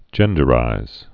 (jĕndə-rīz)